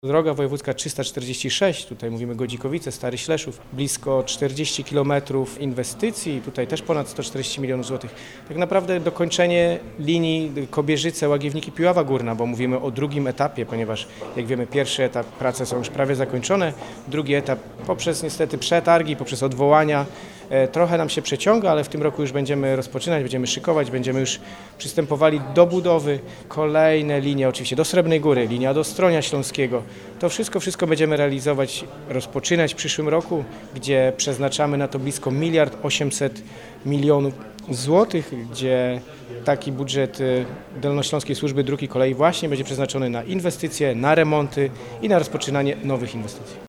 Podczas konferencji prezentującej założenia budżetowe członkowie zarządu województwa podkreślili, że przyszłoroczny budżet będzie większy od tegorocznego o 800 mln zł.
Jest też dobra informacja dla południa regionu – po 50 latach wróci połączenie kolejowe do Srebrnej Góry, wylicza wicemarszałek województwa Michał Rado.